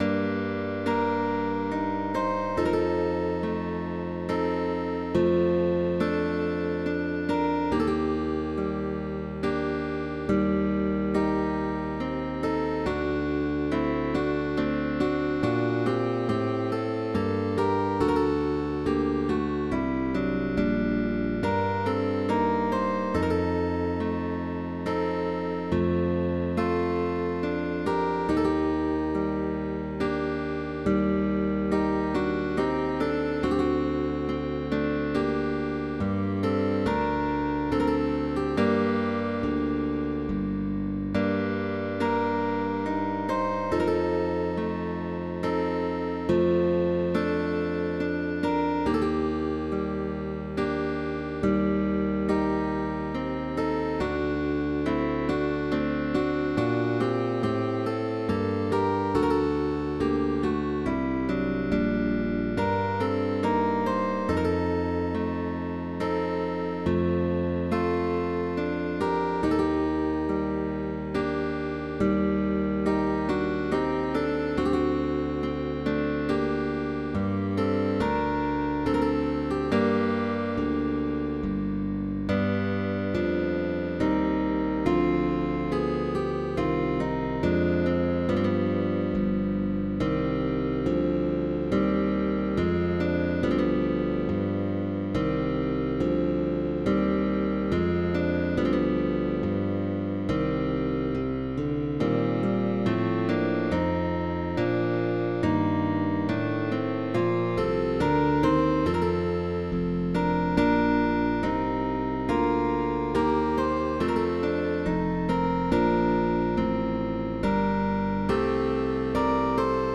With an optional bass, suitable for a guitar orchestra.
Baroque